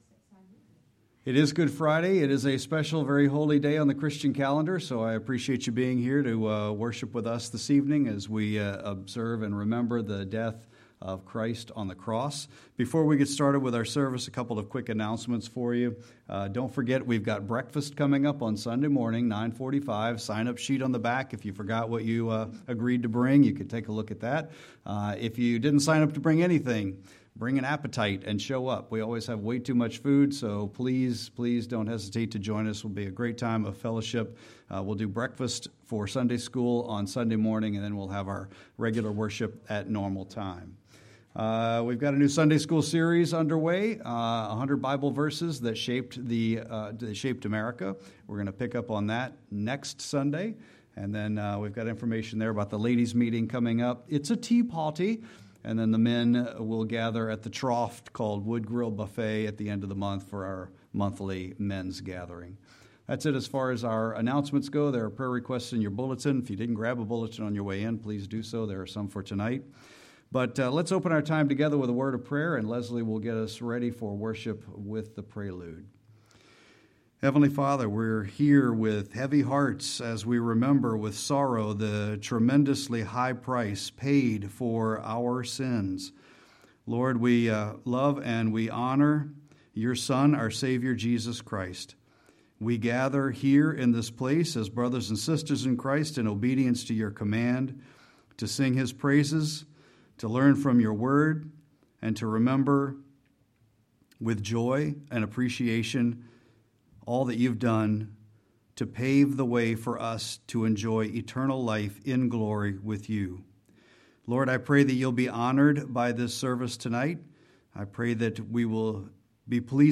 Sermon-4-3-26.mp3